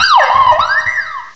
Add all new cries
cry_not_cinccino.aif